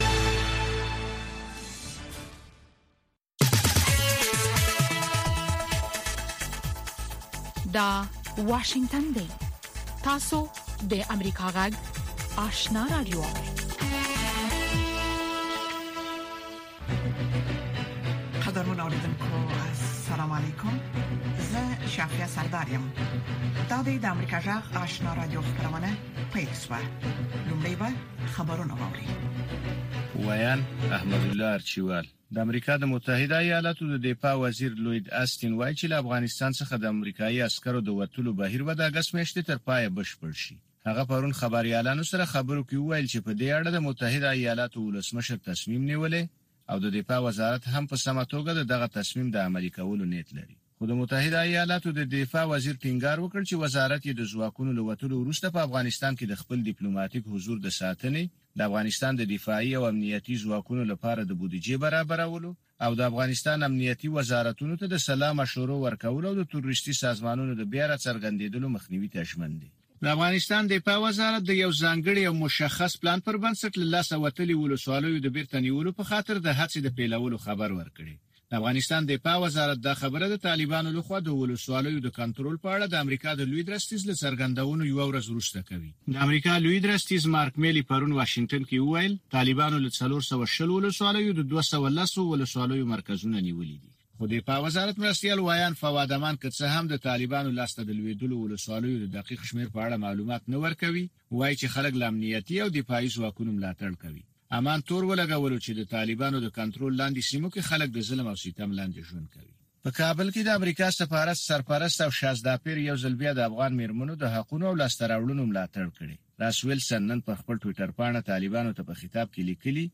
دوهمه ماښامنۍ خبري خپرونه